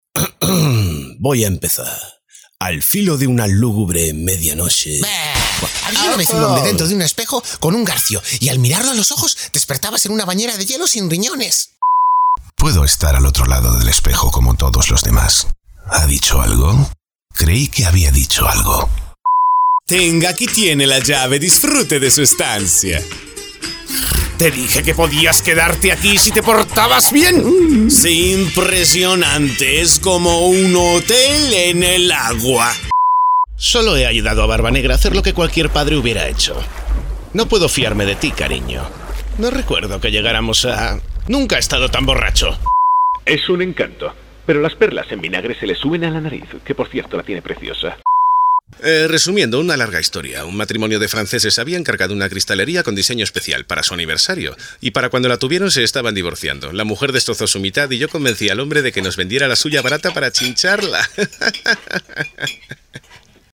Voz amable y cercana para documentales y anuncios, galán para películas o series y mediana edad para realities y voice-overs.
kastilisch
Sprechprobe: Sonstiges (Muttersprache):
Friendly and close voice for documentaries and commercials, gallant for movies or series and middle age for realities and voice-overs.
Demo MIX Registros Doblaje 2020.mp3